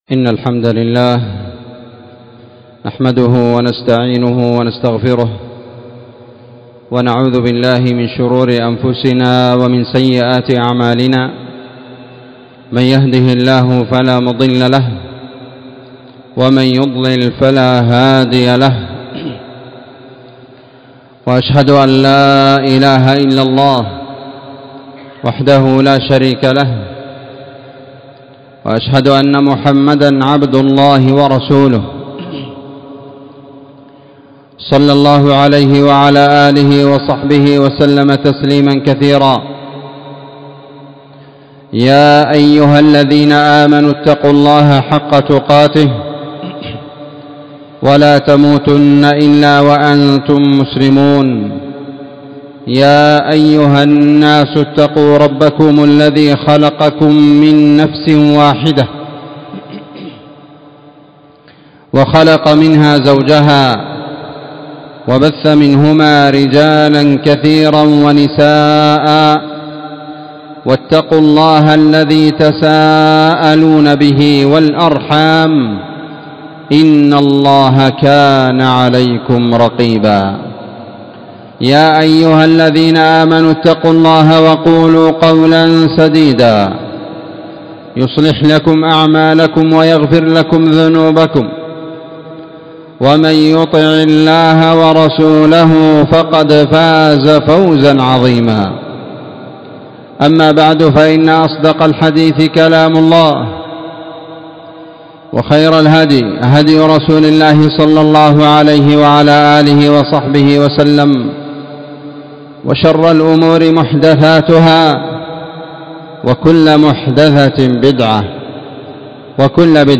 خطبة جمعة